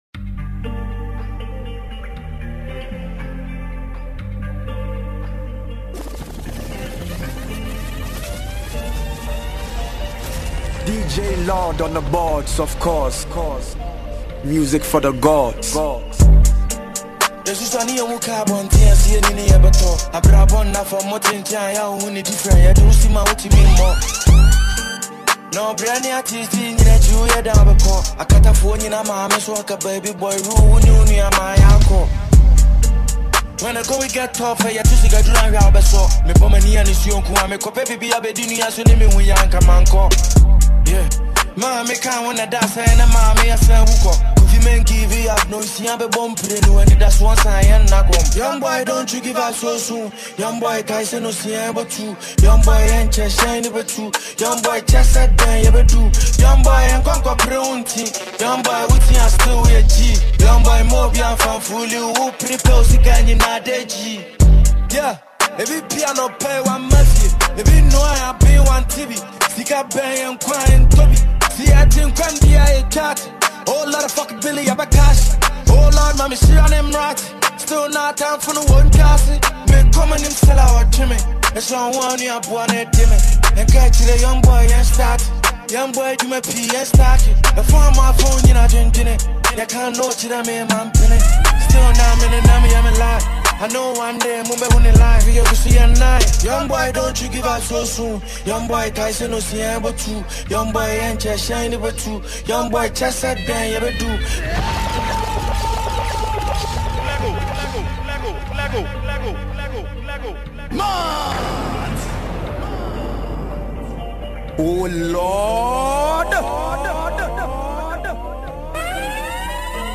Genre: Mixtape Release Format Type